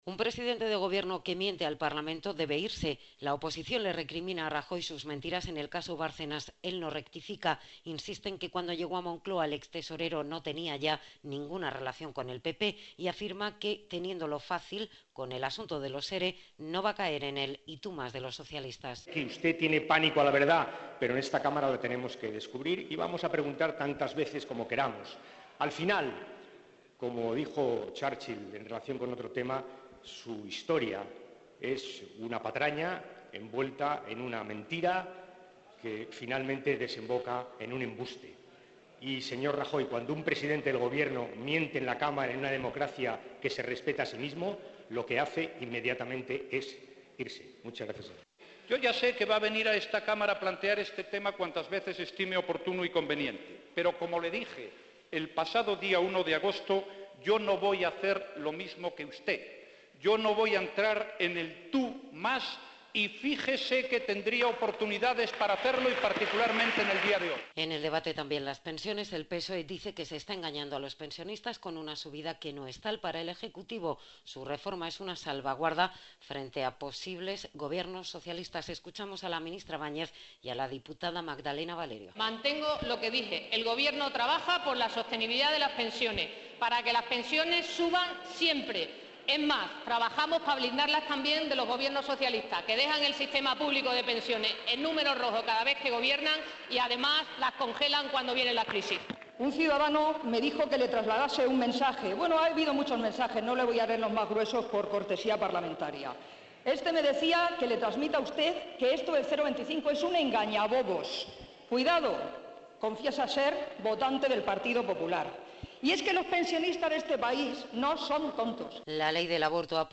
Rajoy ha respondido de esta forma ante el pleno de la Cámara Baja a Rubalcaba, quien le ha preguntado si mantiene que cuando accedió al Gobierno Bárcenas ya no estaba en el PP y le ha pedido que deje su cargo por haber mentido al Parlamento.